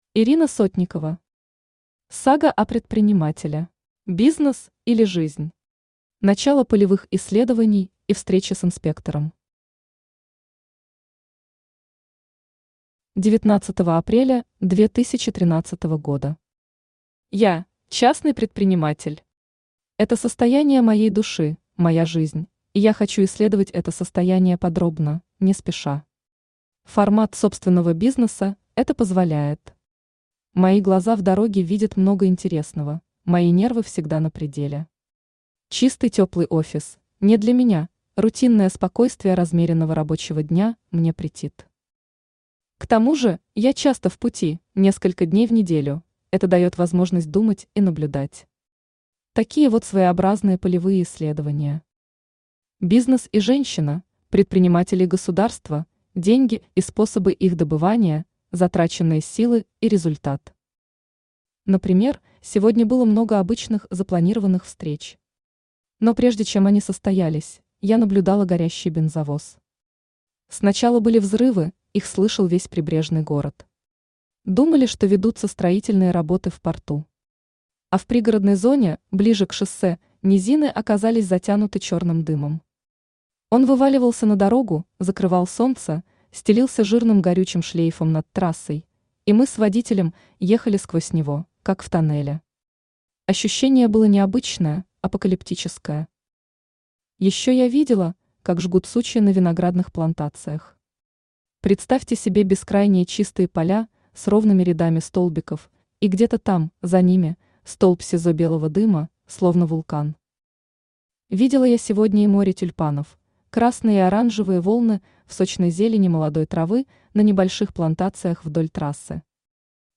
Aудиокнига Сага о предпринимателе Автор Ирина Сотникова Читает аудиокнигу Авточтец ЛитРес.